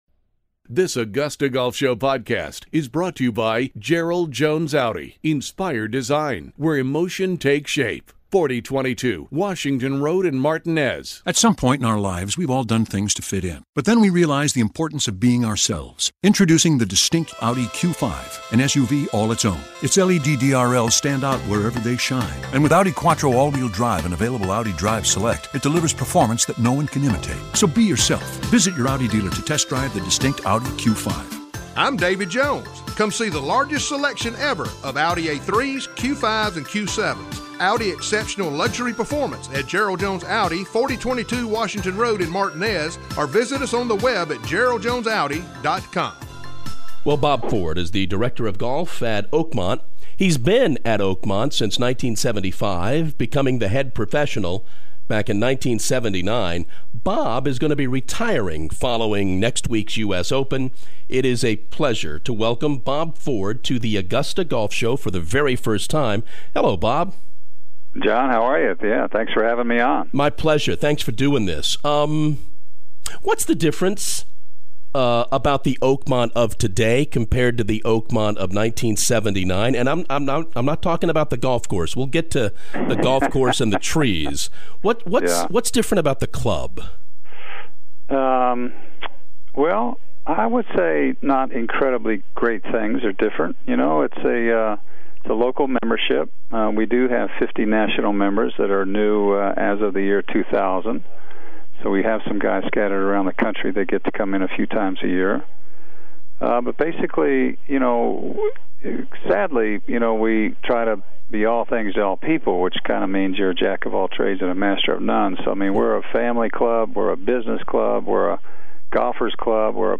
The Augusta Golf Show Interview